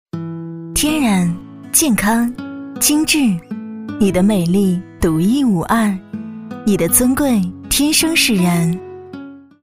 13 女国152_广告_化妆品_化妆品广告_温柔 女国152
女国152_广告_化妆品_化妆品广告_温柔.mp3